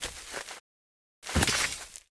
drop_2.wav